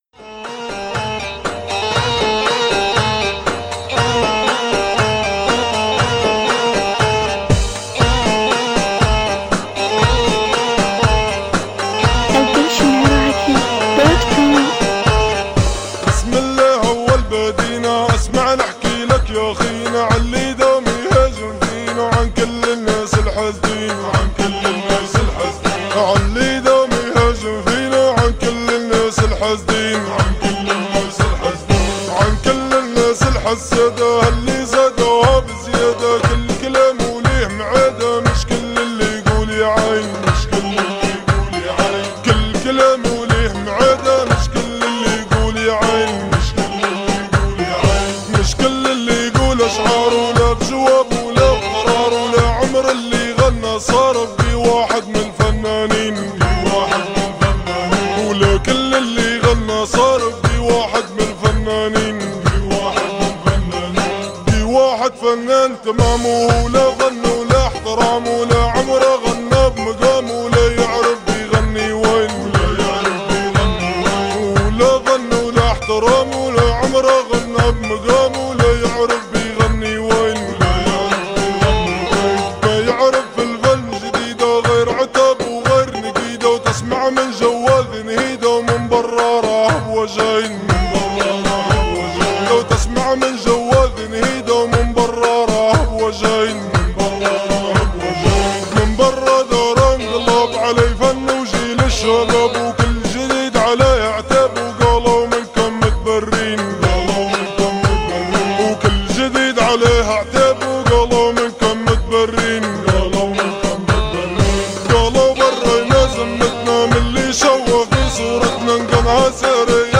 اغاني ليبية 2017